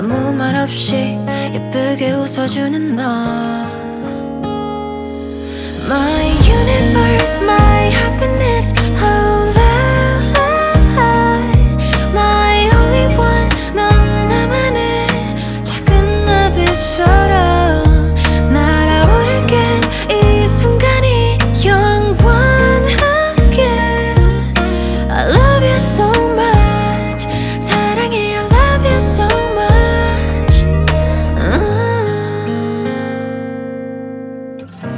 Жанр: R&B / Альтернатива / Соул